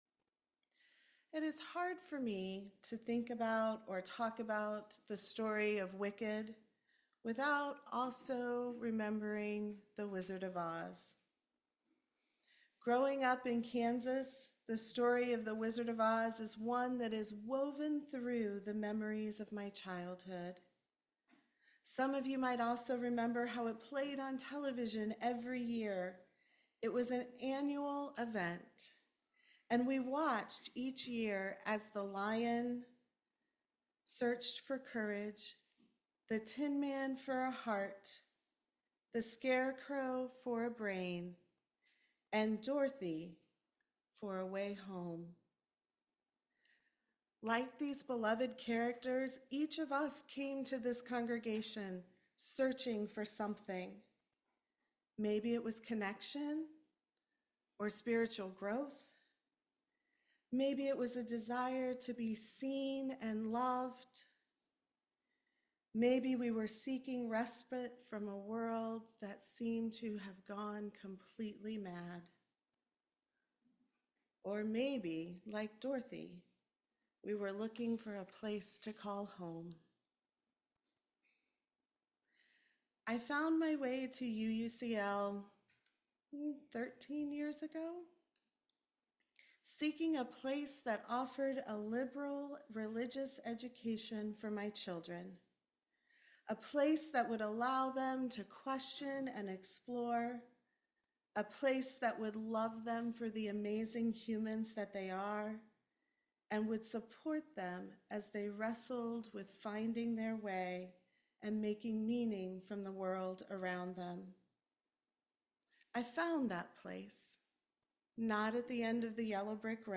In this reflective sermon, the speaker explores the themes of transformation and belonging by weaving together personal anecdotes with the narratives of The Wizard of Oz and Wicked.